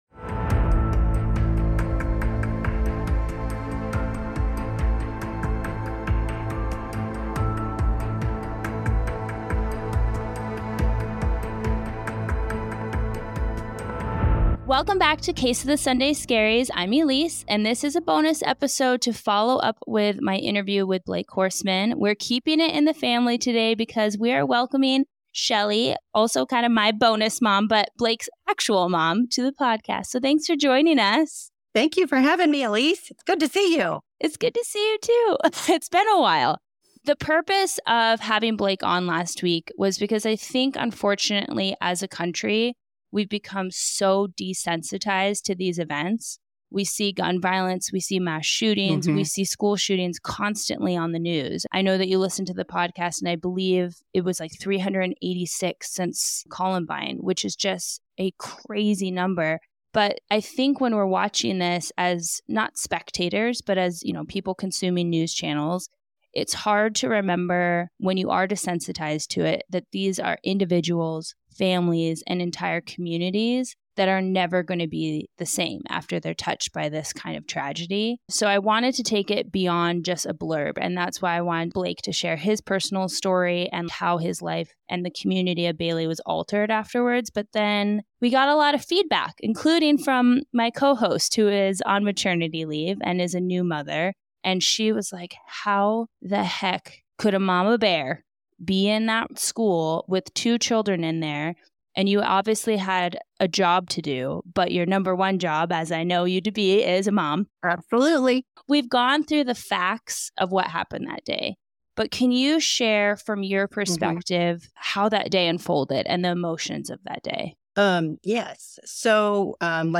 055 : Aftermath - Bonus Interview